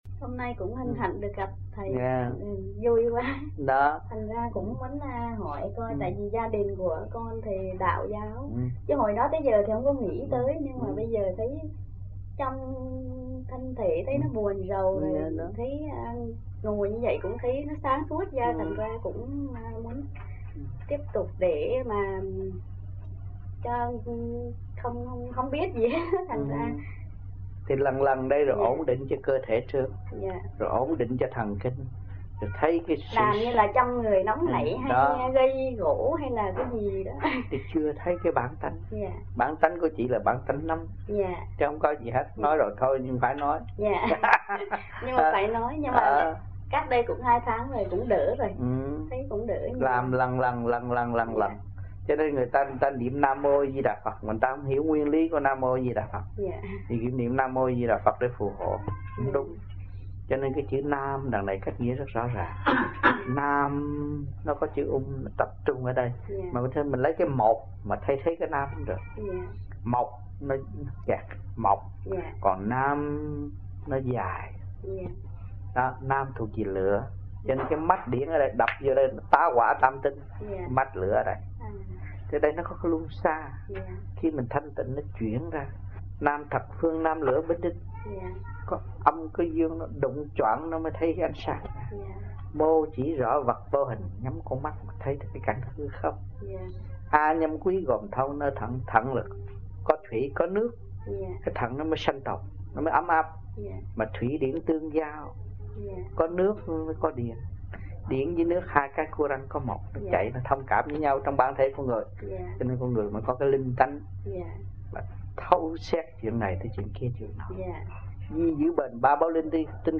1980-11-20 - AMPHION - THUYẾT PHÁP 08